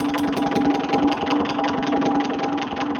RI_DelayStack_80-06.wav